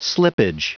Prononciation du mot slippage en anglais (fichier audio)
Prononciation du mot : slippage